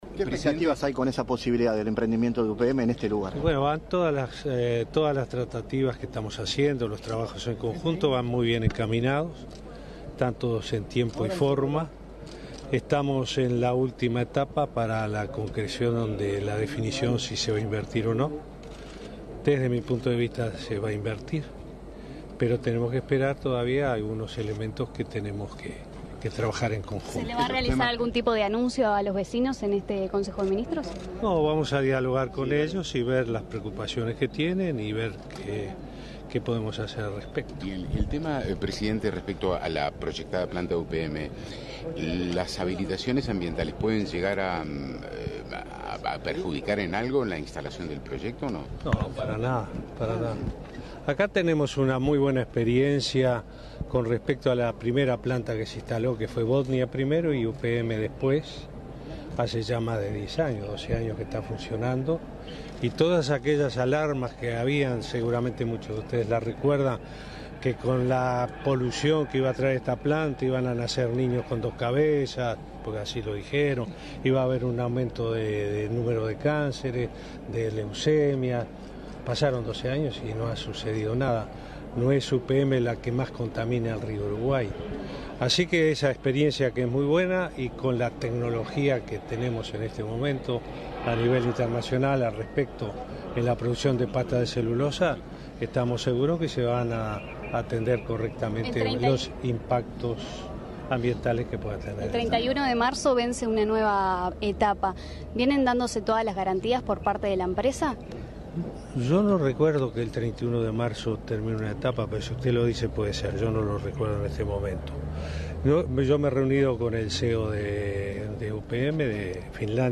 “Hubo muchas alarmas, pero pasaron 12 años y no ha sucedido nada”, afirmó el presidente Tabaré Vázquez en Durazno, al destacar que Uruguay tiene una muy buena experiencia en plantas de celulosa. Previo al Consejo de Ministros abierto en Pueblo Centenario, dijo a la prensa que es optimista con la concreción de una nueva planta en el centro del país.